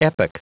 Prononciation du mot epoch en anglais (fichier audio)
Prononciation du mot : epoch